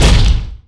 SFX monster_smash_giant.wav